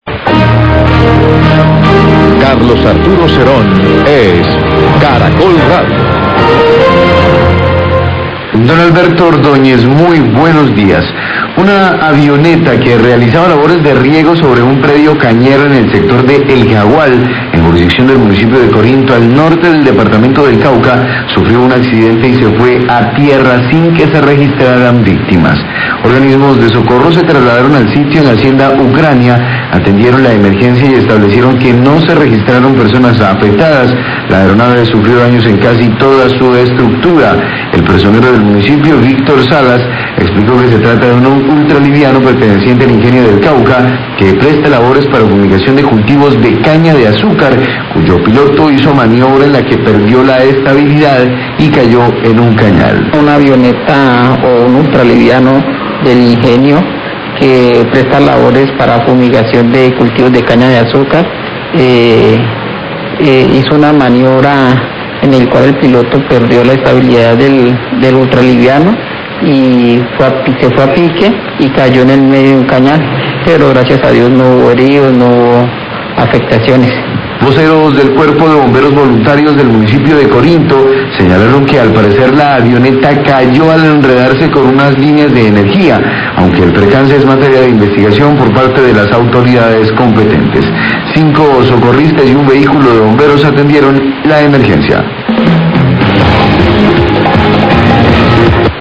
Radio
Declaraciones del Personero de Corinto, Víctor Salas.